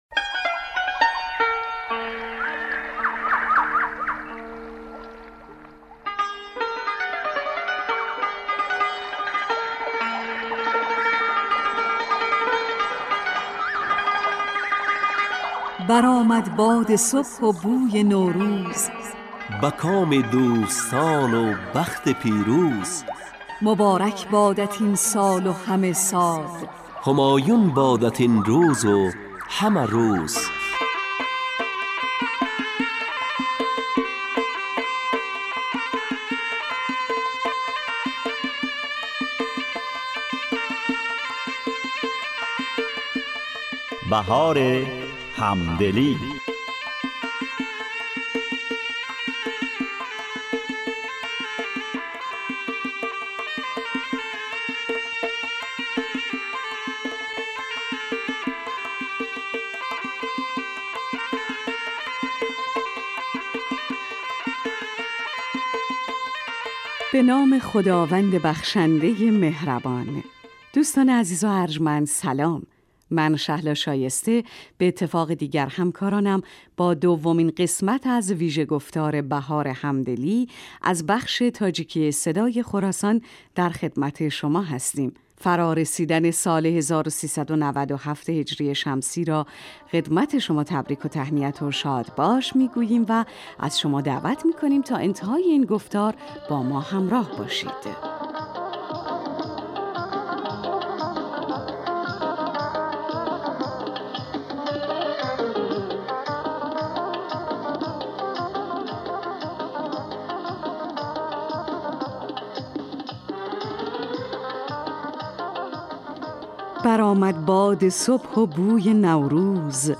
"Баҳори ҳамдилӣ" - вижабарномаи наврӯзии радиои тоҷикии Садои Хуросон аст, ки ба муносибати айёми Наврӯз дар ин радио ба муддати 30 дақиқа таҳия ва пахш мешавад.